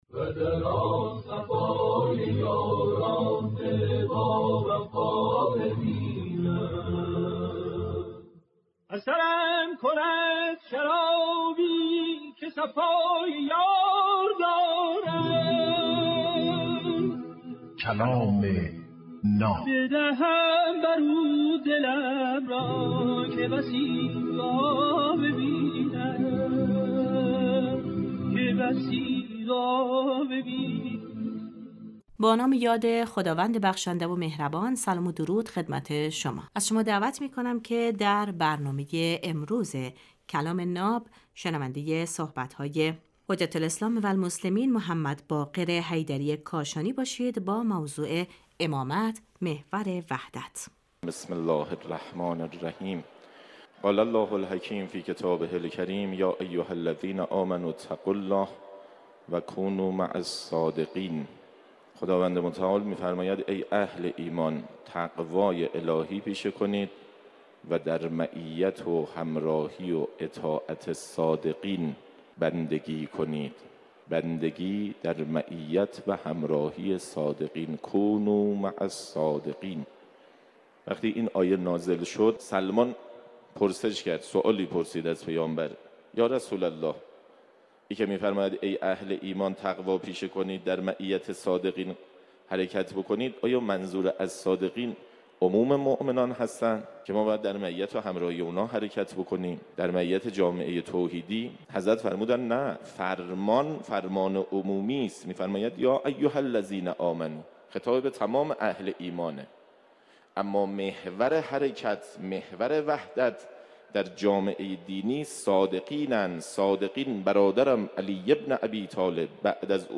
در این برنامه هر روز یک سخنرانی آموزنده کوتاه پخش می شود.